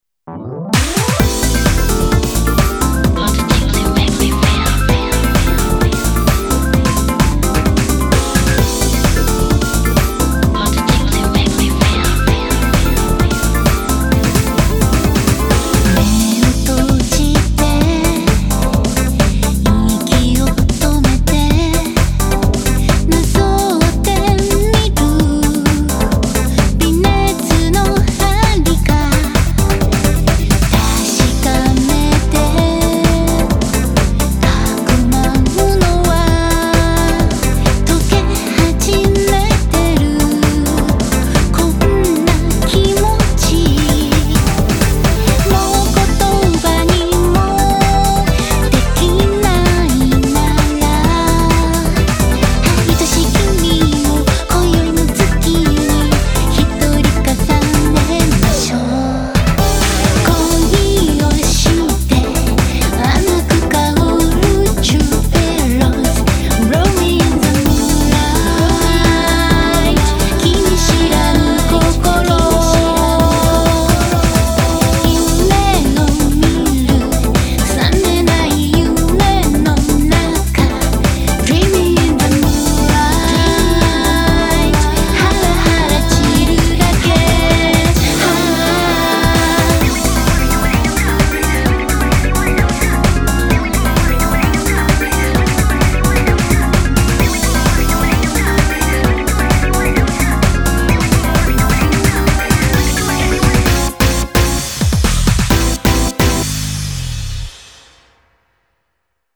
BPM130
Audio QualityPerfect (High Quality)
house
lovely voice